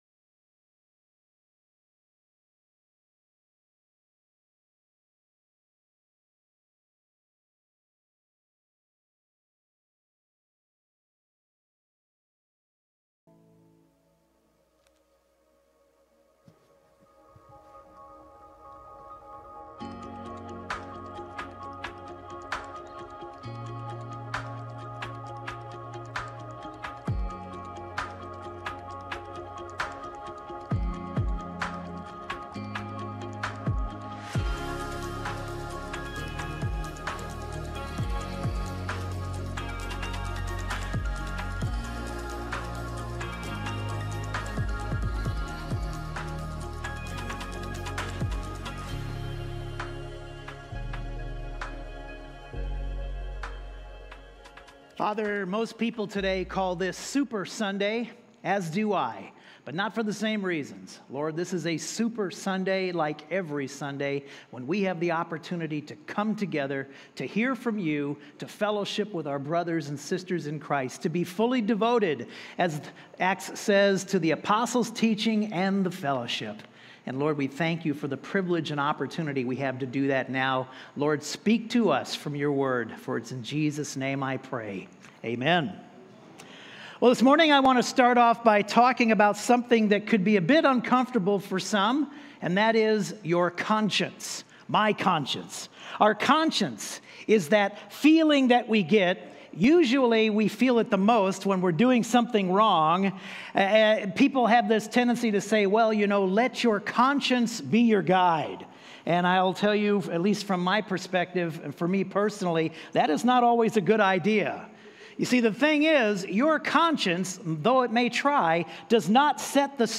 Sermons | CrossWinds Church